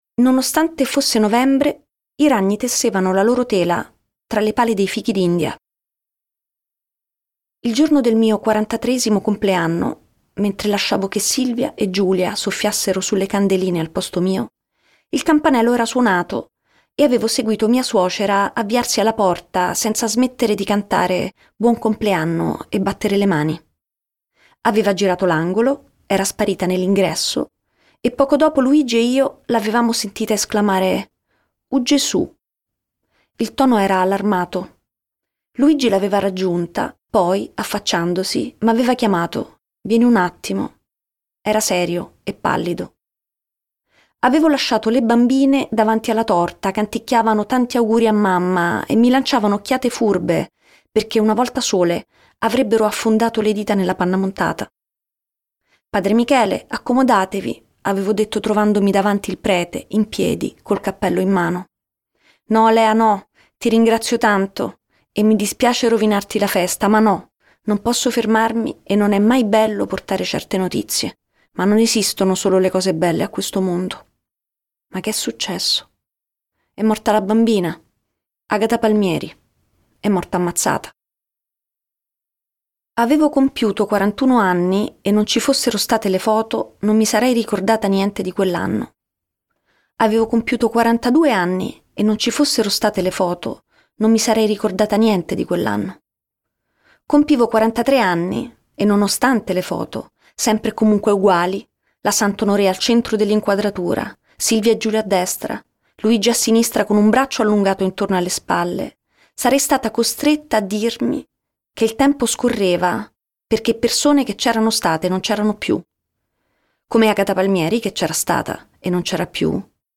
letto da Chiara Valerio
Versione audiolibro integrale